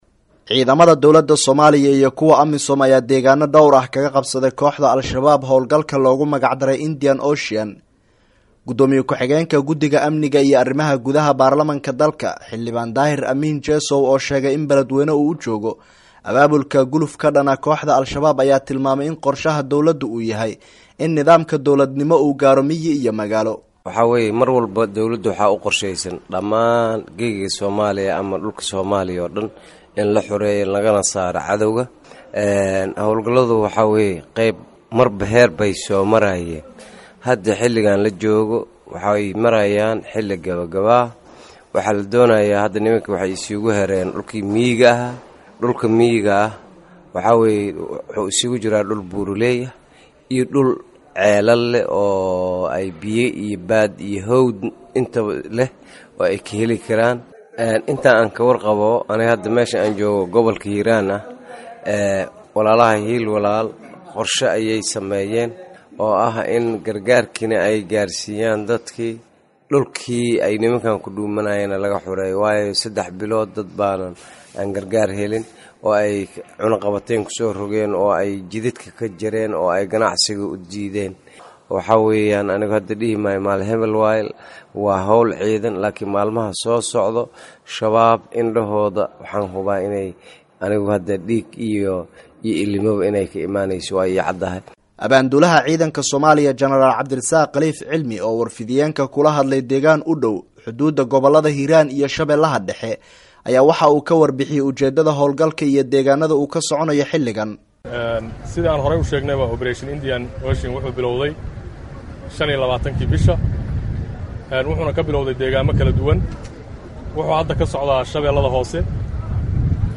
Warbixin: Hawlgalka Indian Ocean